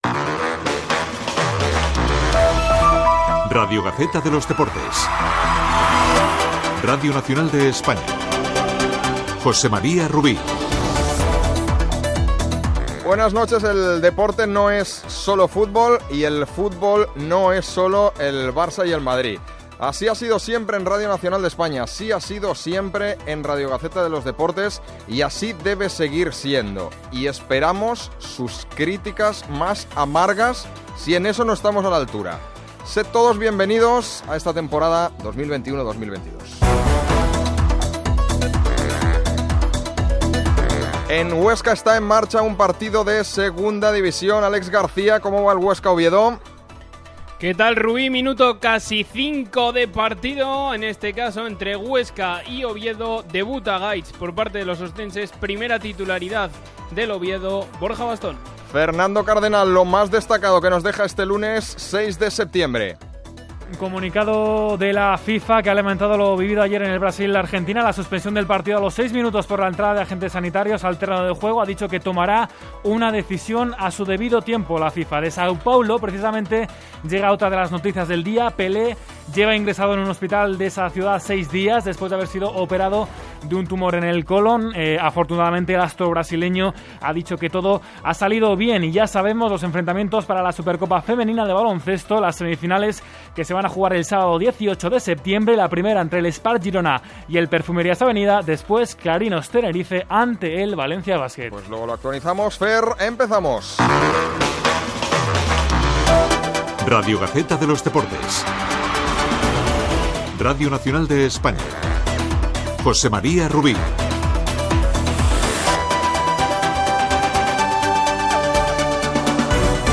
Primera edició del programa presentada pel nou equip, des de Barcelona. Declaracions d'intencions i benvinguda. Connexió amb el partit de futbol masculí Huesca -Oviedo.
Gènere radiofònic Esportiu